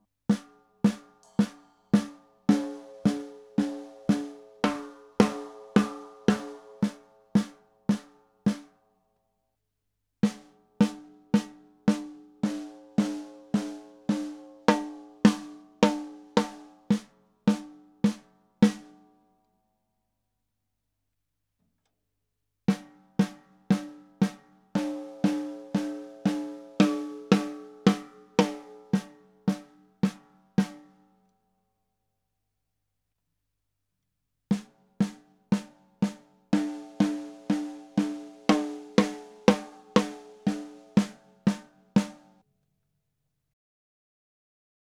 4 Snares im Vergleich
snaredrums.wav